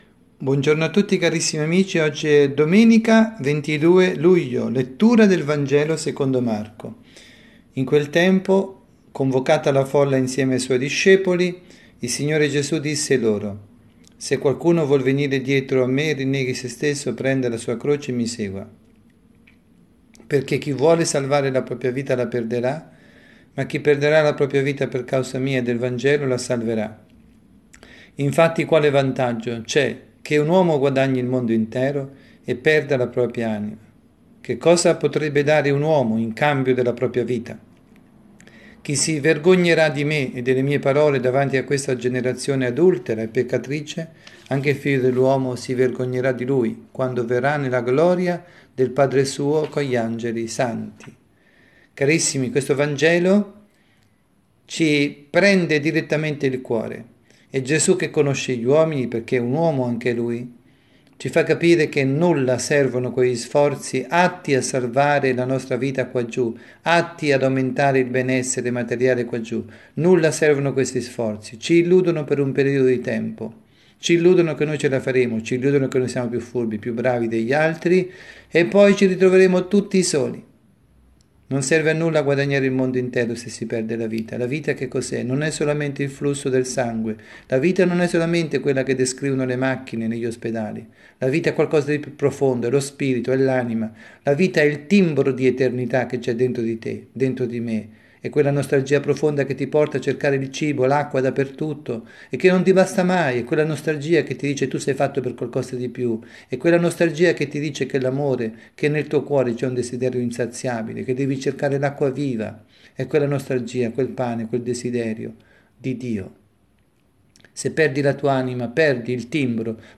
Omelia
dalla Parrocchia S. Rita – Milano